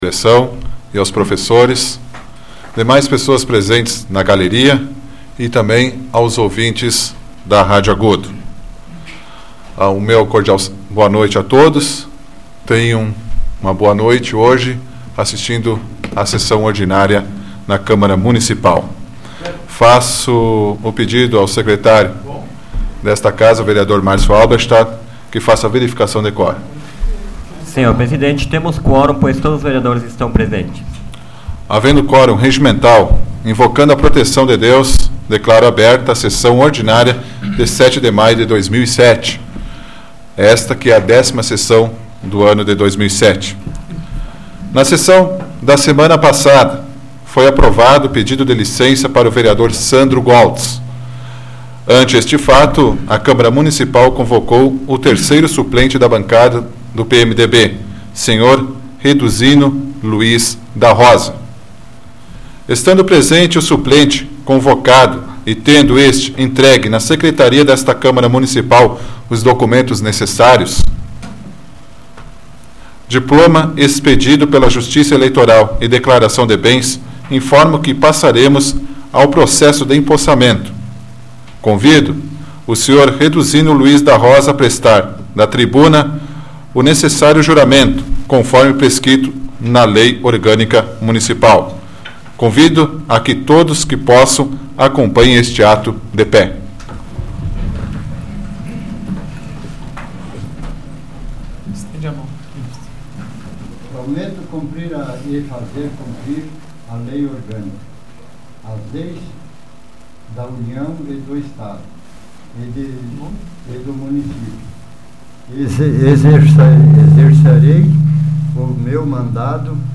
Áudio da 84ª Sessão Plenária Ordinária da 12ª Legislatura, de 07 de maio de 2007